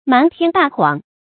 瞒天大谎 mán tiān dà huǎng 成语解释 指天大的谎话。形容漫无边际的假话 成语出处 明 兰陵笑笑生《金瓶梅词话》第七回：“把偏房说成正房，一味 瞒天大谎 ，全无半点真实。”